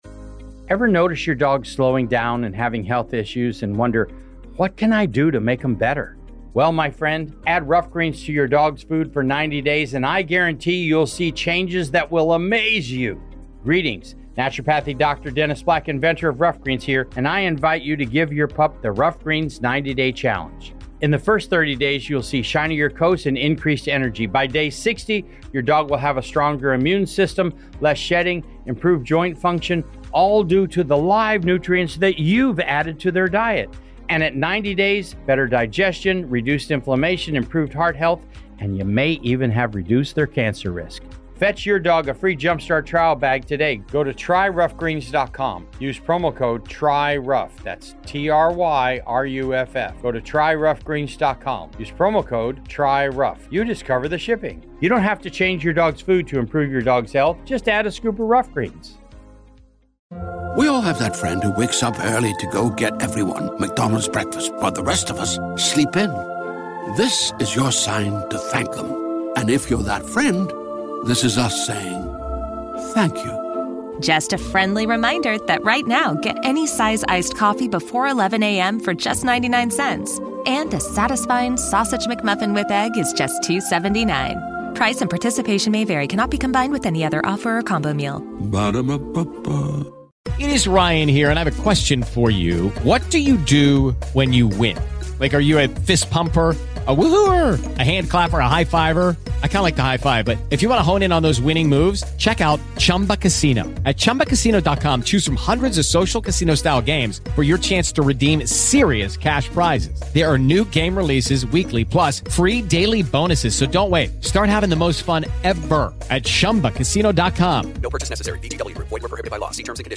The radio series was first broadcast on NBC Red Network on November 1, 1937.
The show was also known for its realistic sound effects, which were created by sound engineer Fred Waring.